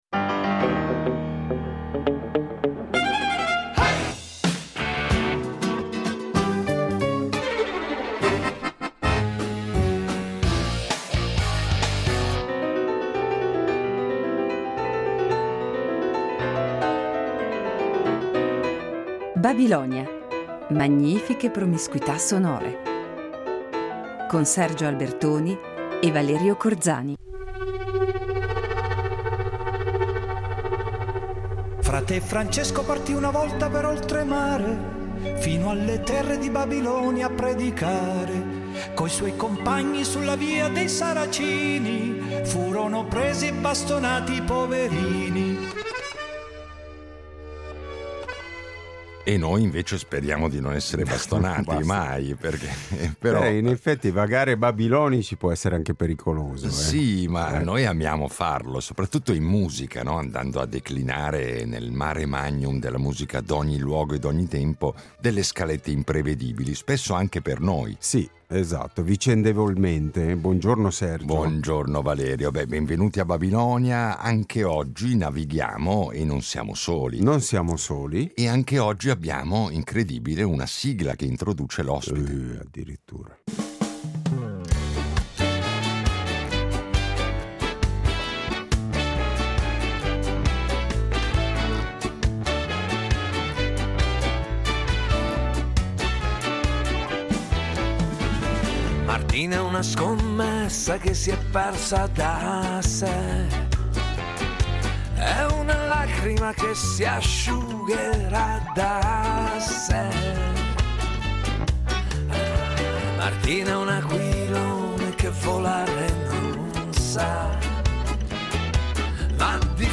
Incontro con la cantautrice e compositrice romana